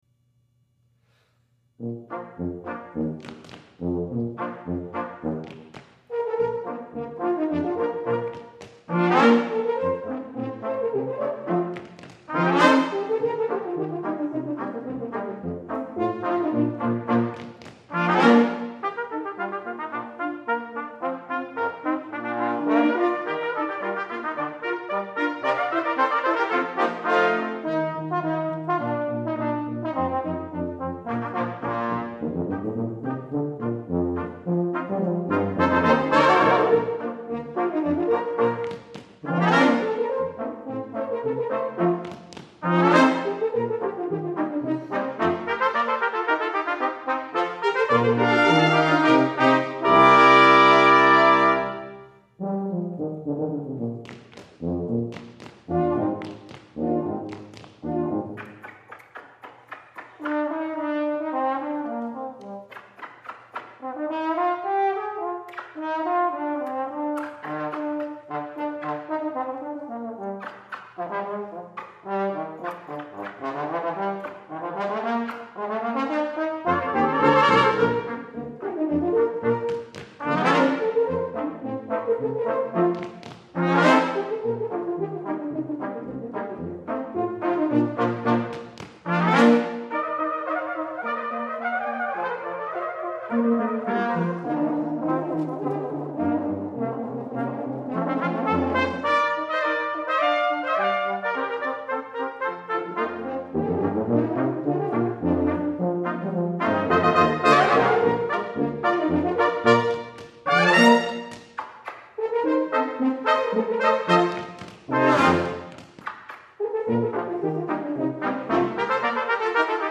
for Brass Quintet (2000)
is quoted and animated by agile brass players!